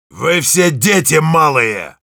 Sounds Yell Rus
Heavy_yell11_ru.wav